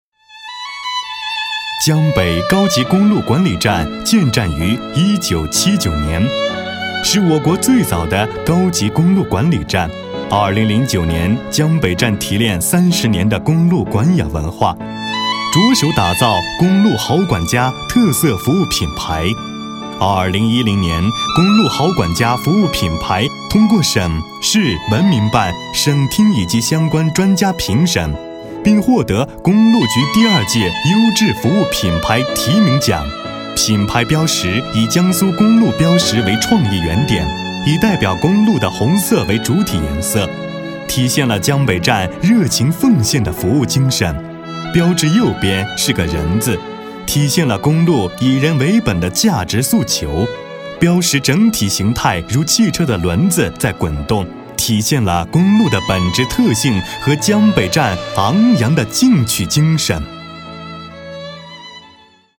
男声配音